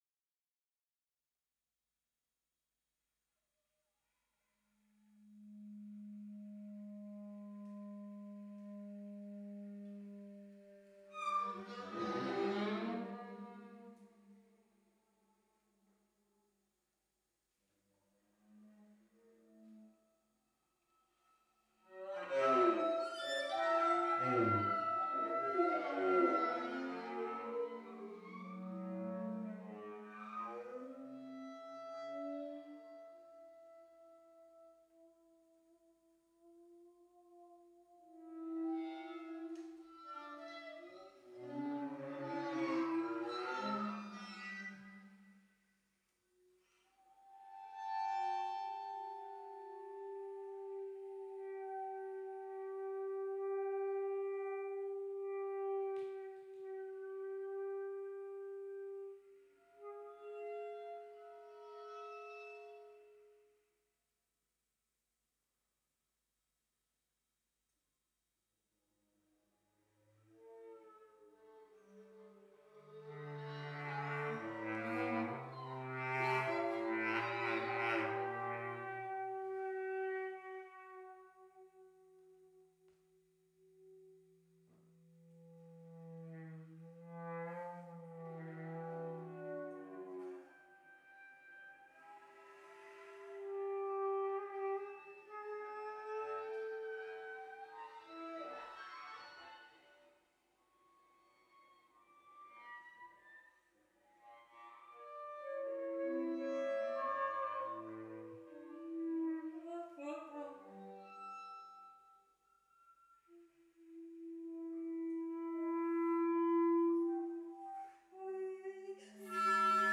Chamber music